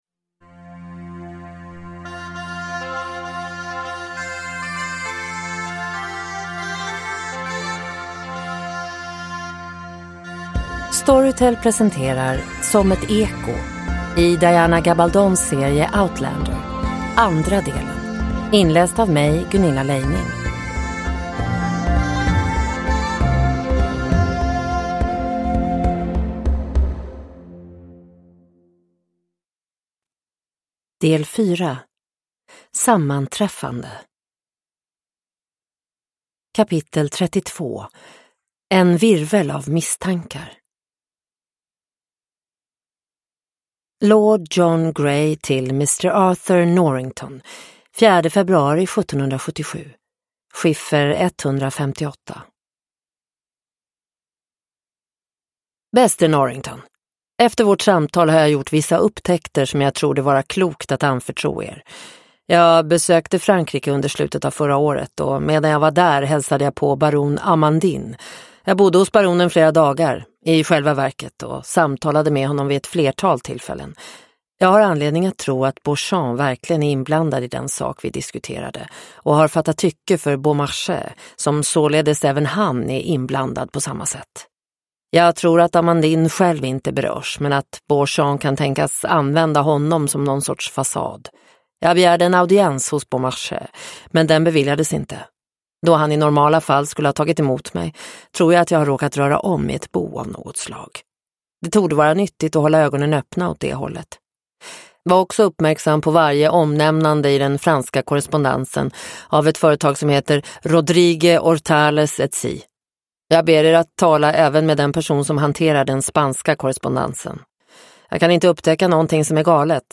Som ett eko - del 2 – Ljudbok – Laddas ner